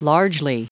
Prononciation du mot largely en anglais (fichier audio)
Prononciation du mot : largely